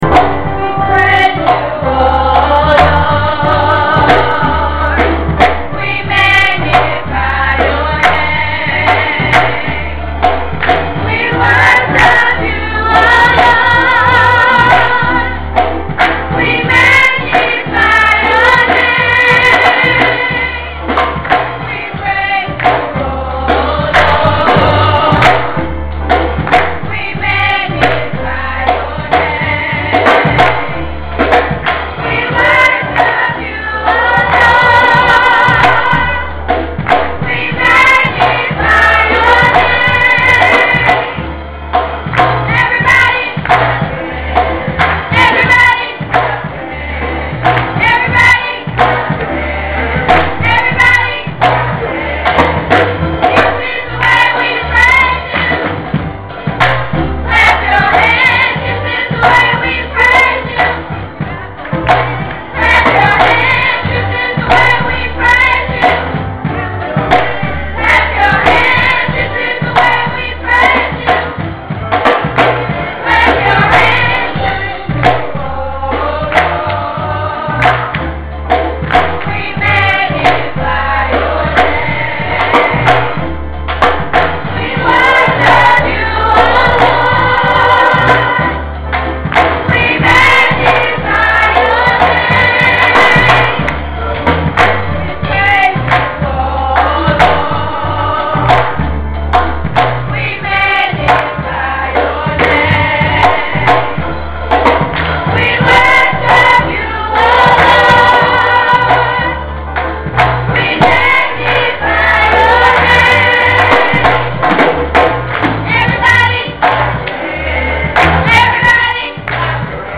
Click here to hear the Director's Address